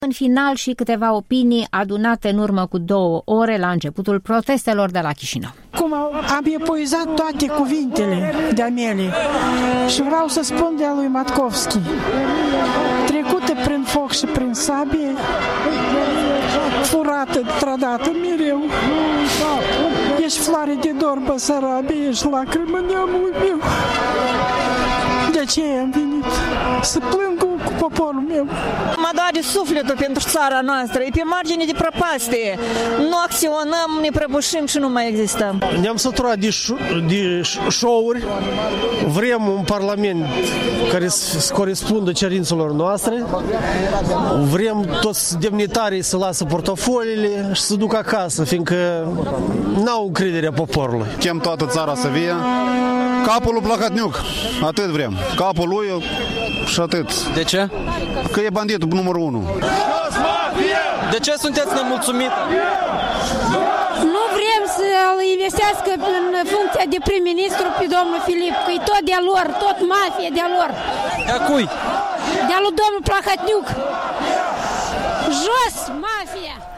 Vox pop pe fundal de proteste la Chișinău